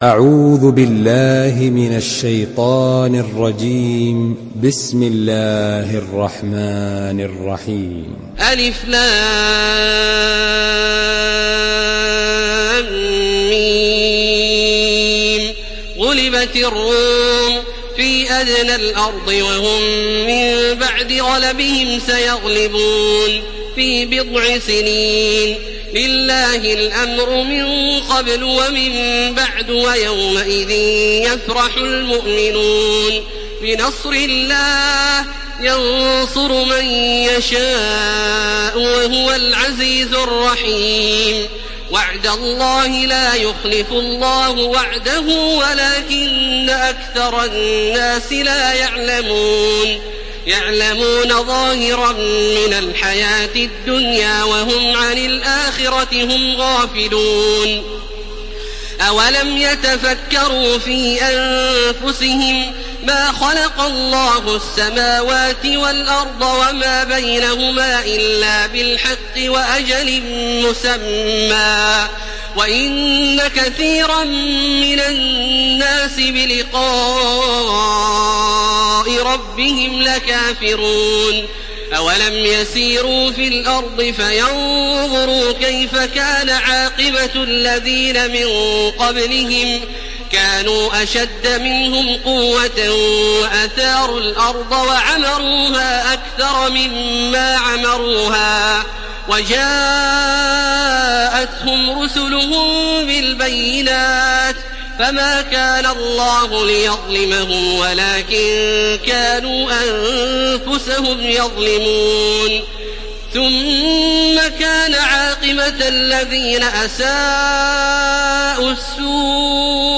Surah Ar-Rum MP3 by Makkah Taraweeh 1431 in Hafs An Asim narration.
Murattal Hafs An Asim